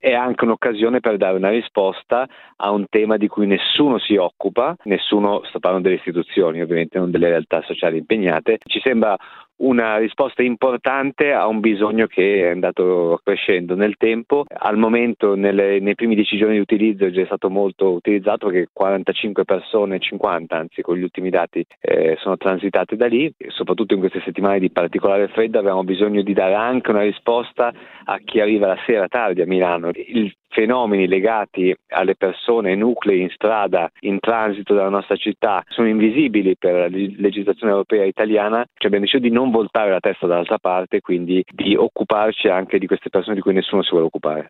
Fino a poco tempo fa, su 1500 posti letto messi a disposizione dalle istituzioni, a Milano nessuno era riservato ai transitanti. Per loro l’accoglienza era e resta più complicata che per altri, spiega l’assessore comunale al Welfare, Lamberto Bertolè.